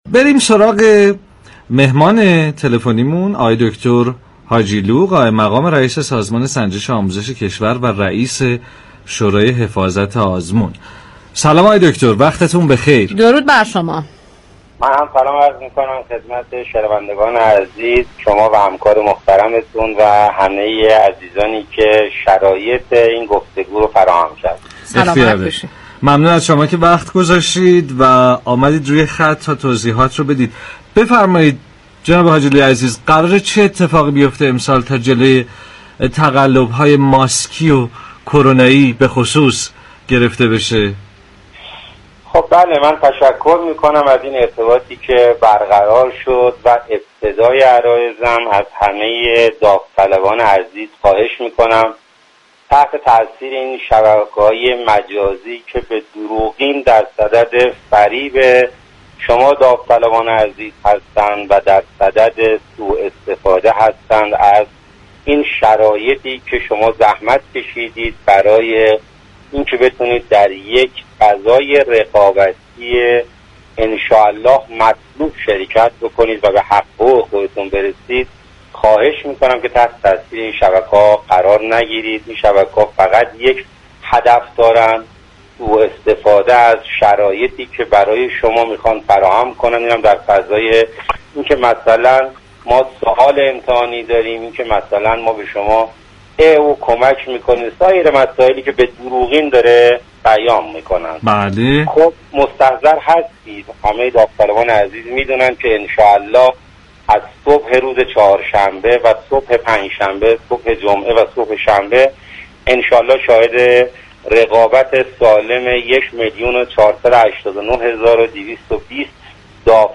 به گزارش پایگاه اطلاع رسانی رادیو تهران، محمدحسین حاجیلو قائم مقام سازمان سنجش كشور و رئیس شورای حفاظت آزمون در گفت و گو با برنامه 2 تیر با اشاره به اینكه شبكه‌هایی در فضای مجازی قصد سوء استفاده از داوطلبان را دارند از آنها خواست تحت تاثیر تبلیغات دروغین شبكه‌های مجازی قرار نگیرند.